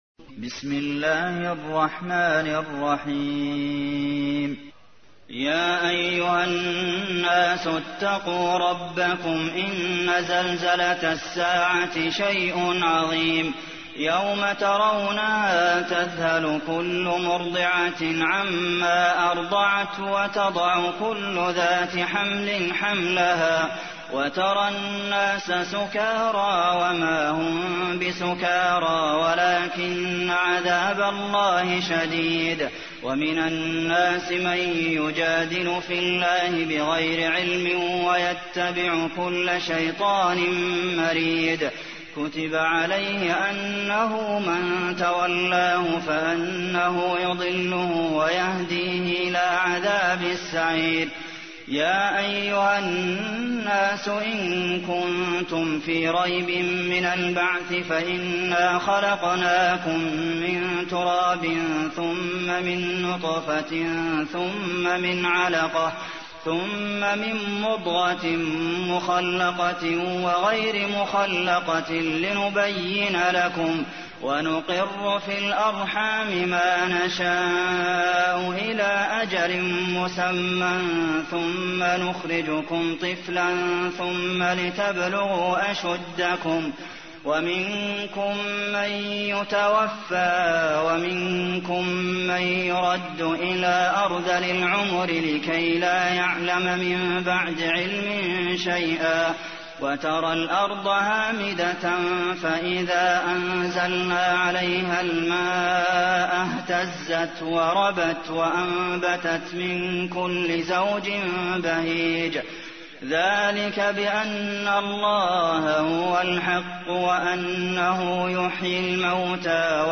تحميل : 22. سورة الحج / القارئ عبد المحسن قاسم / القرآن الكريم / موقع يا حسين